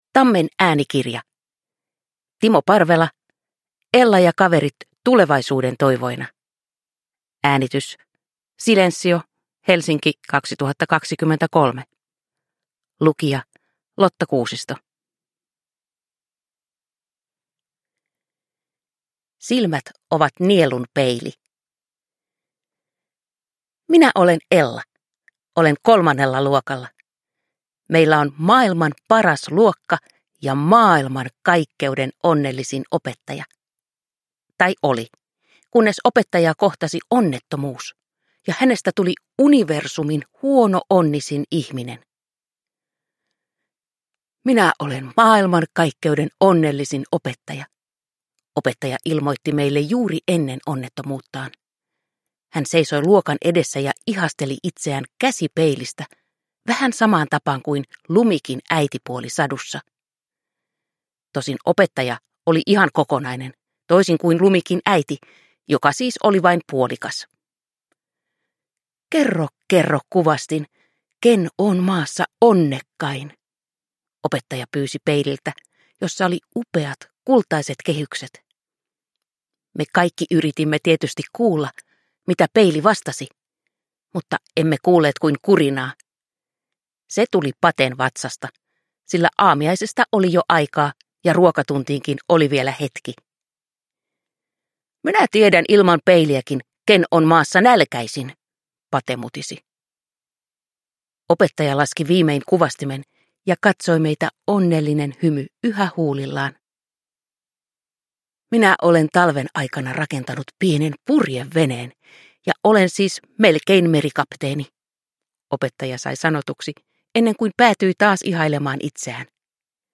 Ella ja kaverit tulevaisuuden toivoina – Ljudbok – Laddas ner